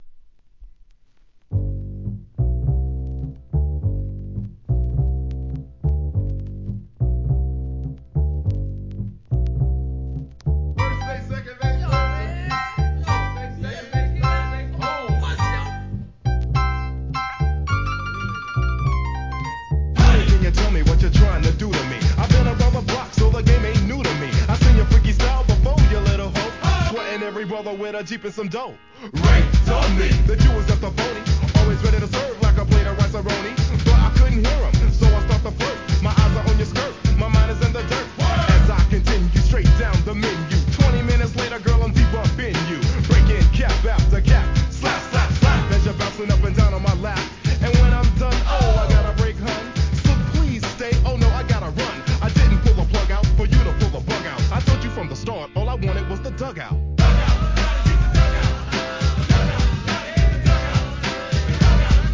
HIP HOP/R&B
コール＆レスポンスもキャッチー!!!